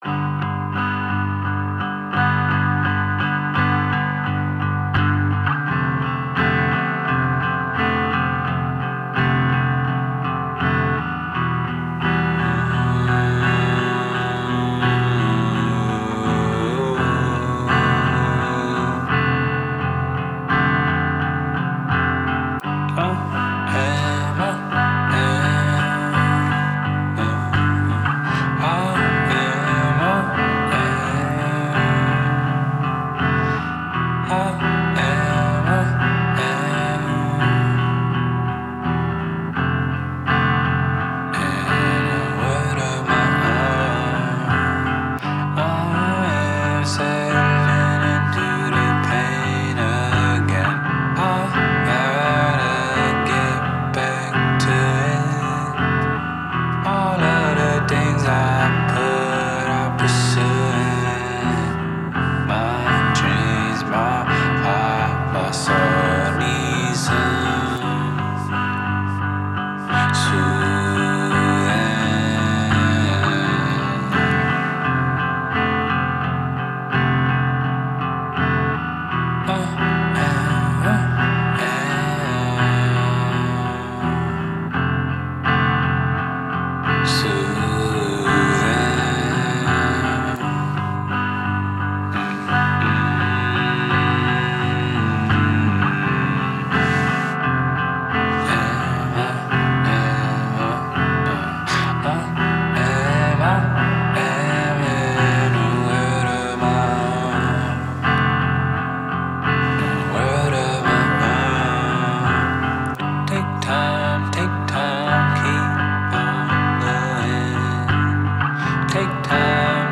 Mindful hip-hop and modern mythology